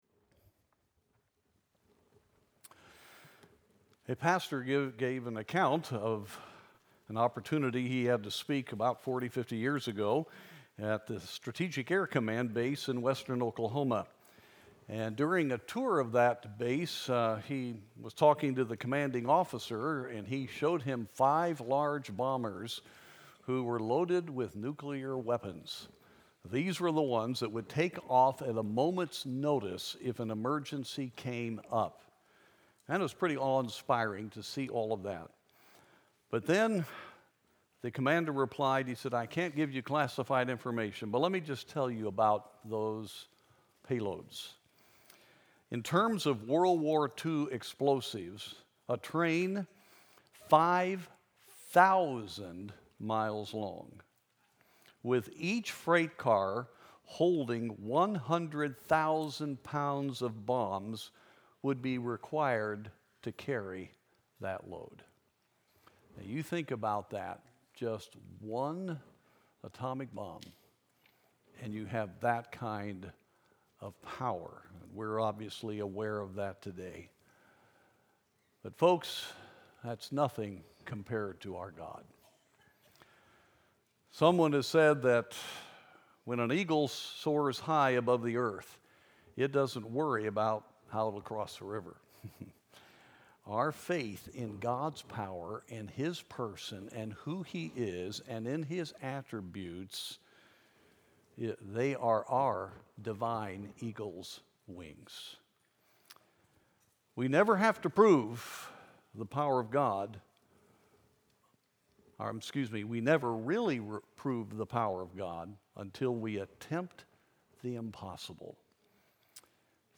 2022-2023 Theme Sermon Series